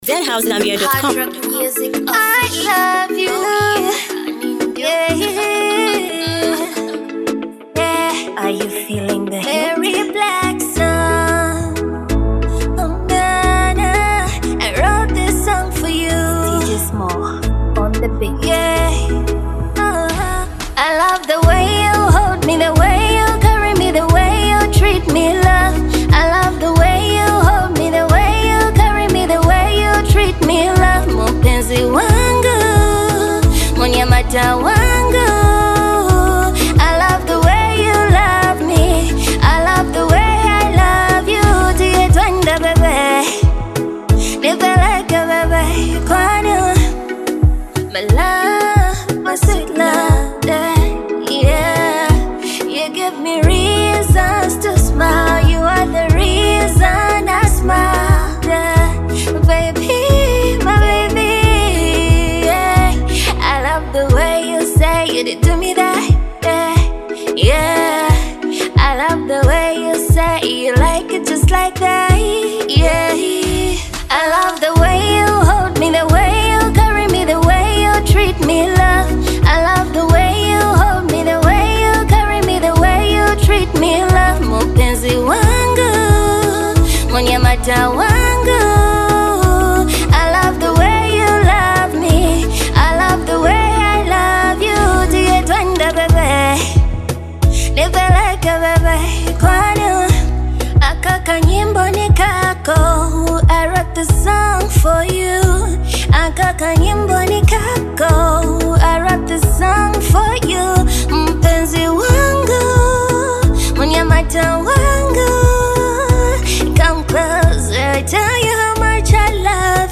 love song
a soulful expression of deep affection and devotion.